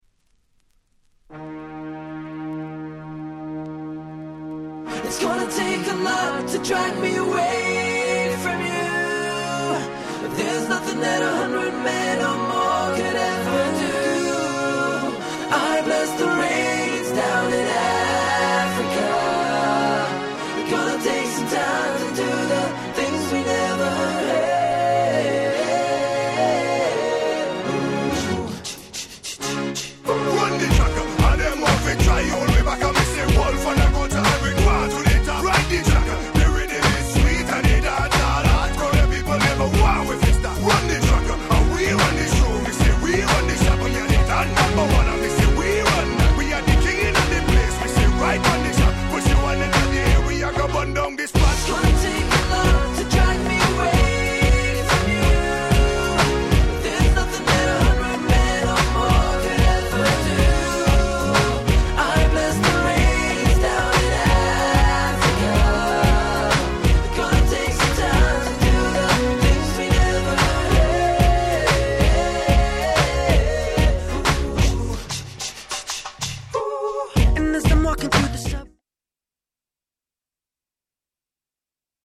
大ヒットDancehall R&B !!
00's レゲエ ダンスホール キャッチー系